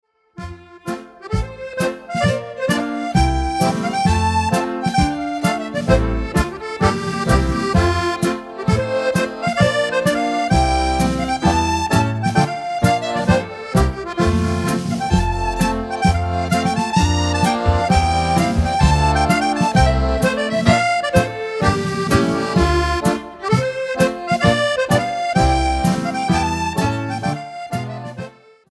4 x 32 Strathspey
accordion
fiddle
drums